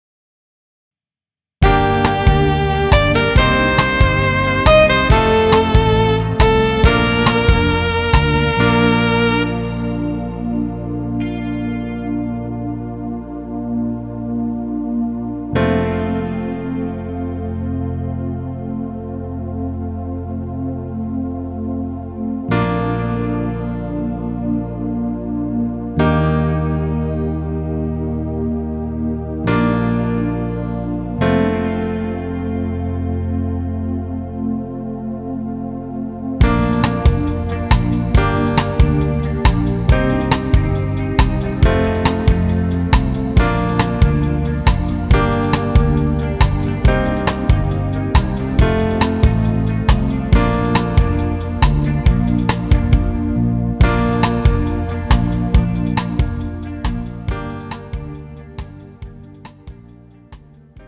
음정 -2키
장르 가요 구분 Pro MR